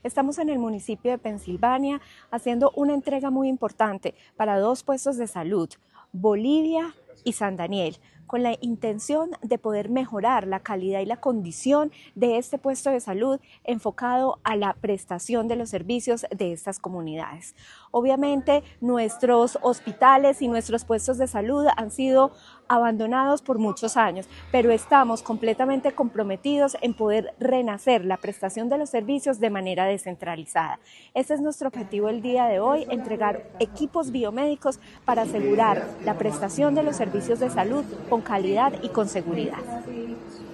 Natalia Castaño Díaz, Directora de la DTSC.